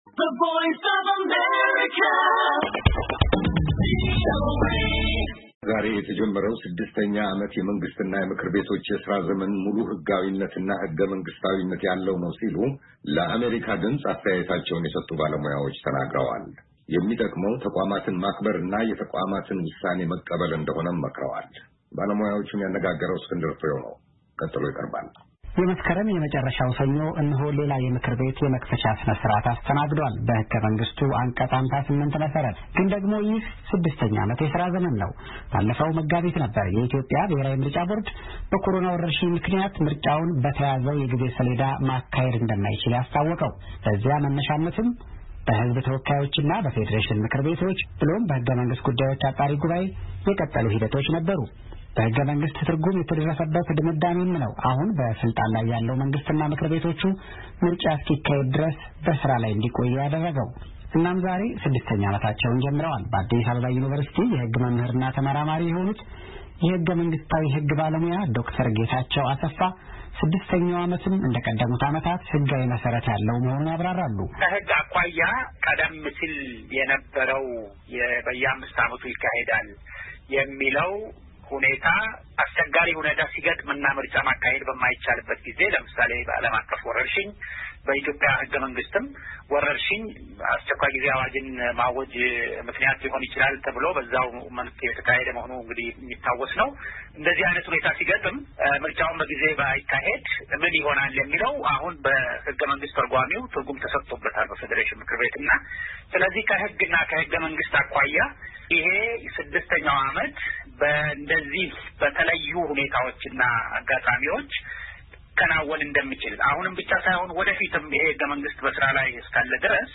ዛሬ የተጀመረው ሰድስተኛ ዓመት የመንግሥትና የምክር ቤቶች የሥራ ዘመን ሙሉ ሕጋዊነትና ሕገመንግስታዊነት ያለው ነው ሲሉ አስተያየቶቻቸውን ለአሜሪካ ድምፅ የሰጡ ባለሙያዎች ተናግረዋል።